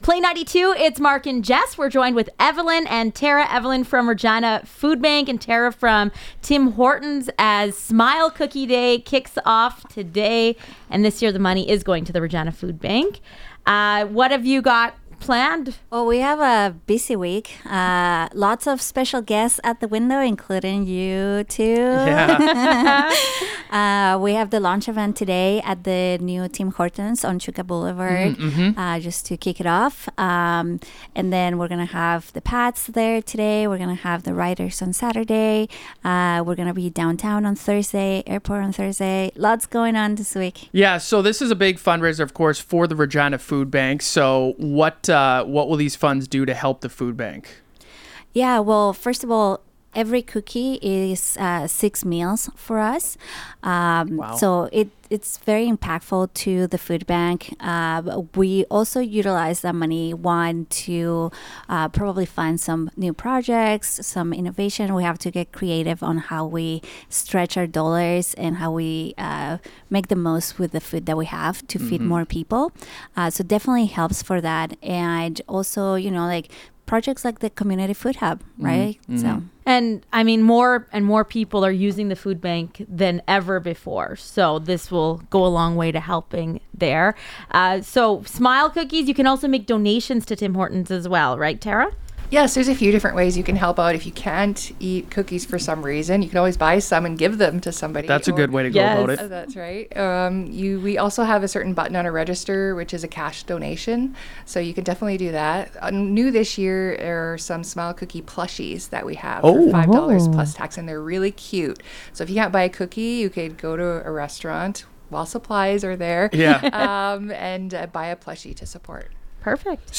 smile-cookie-interview.mp3